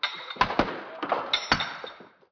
marble1.wav